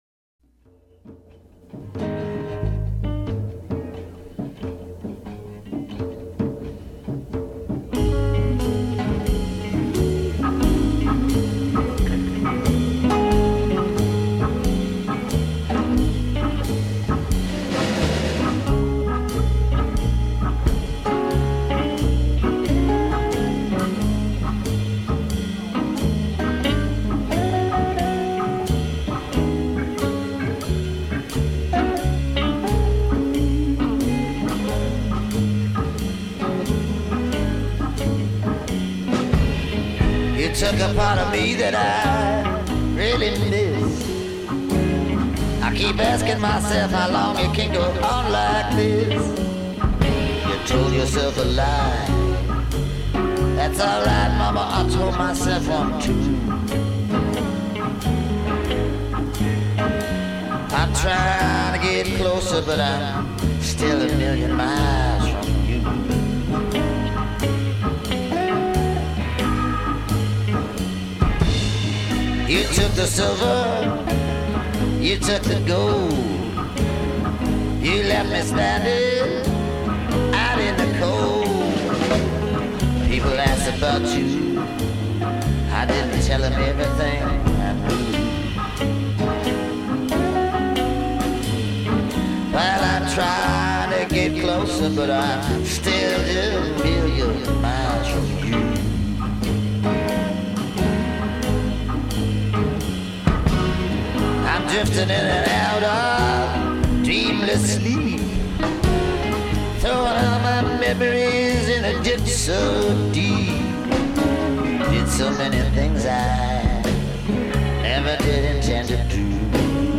gravelly, world-weary vocals